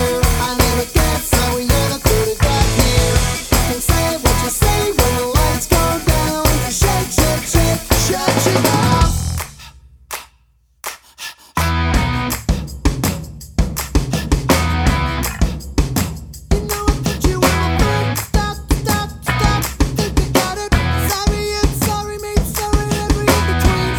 no Backing Vocals For Guitarists 3:08 Buy £1.50